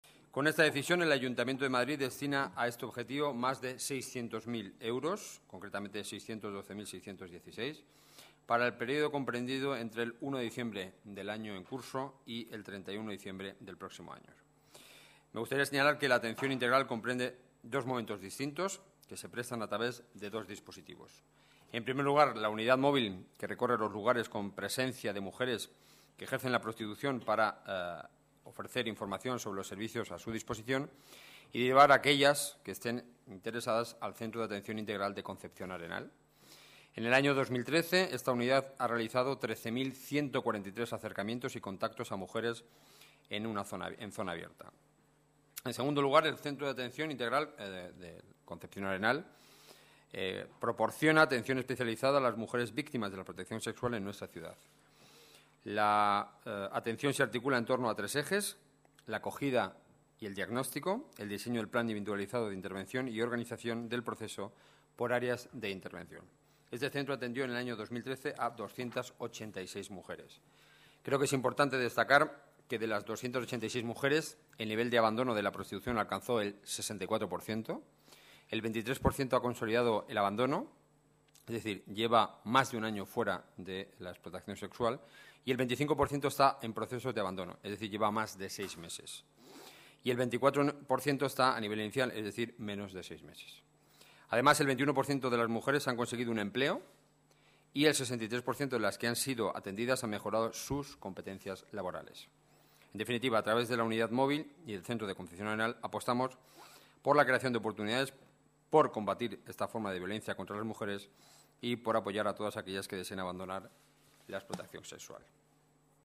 Nueva ventana:Declaraciones del portavoz del Gobierno, Enrique Núñez: Atención Mujeres Explotadas